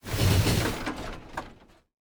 train-tie-5.ogg